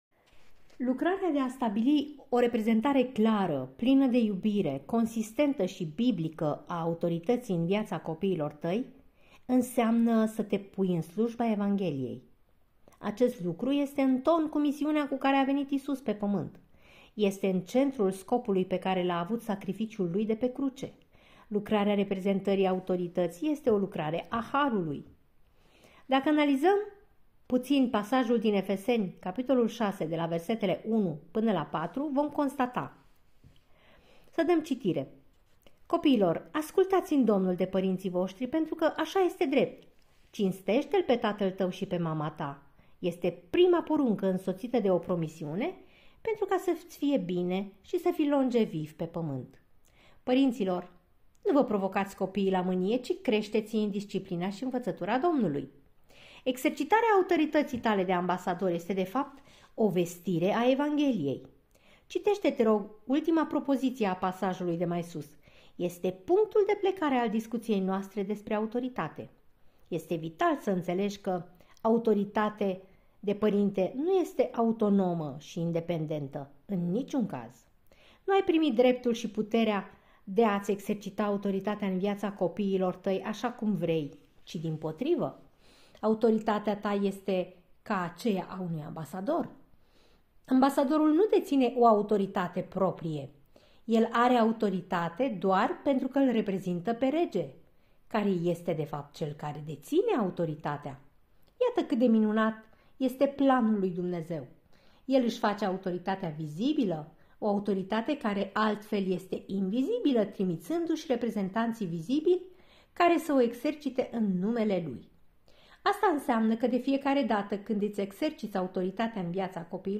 Acest podcast este a doua parte a celui de-al optelea capitol al cărții " Pentru părinți - 14 principii care îți pot schimba radical familia " de la Paul David Tripp.